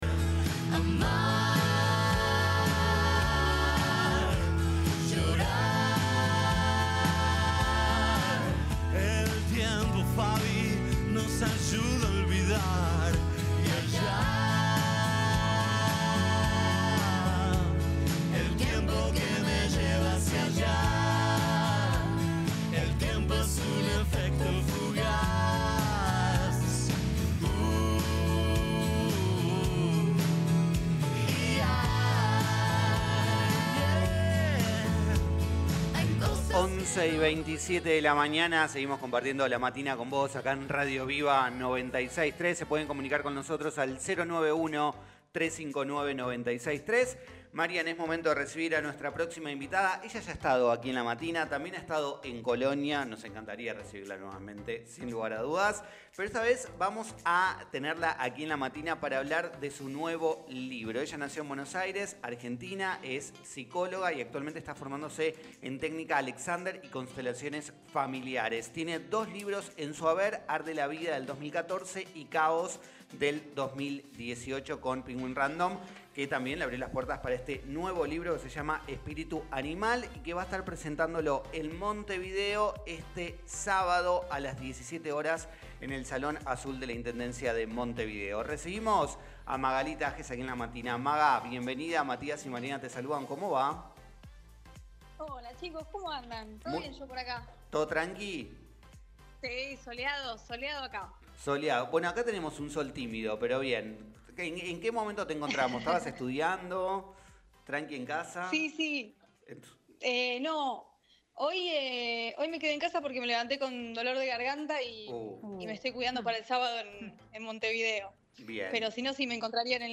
En entrevista exclusiva para el programa La Matina de Radio Viva 96.3 FM de Colonia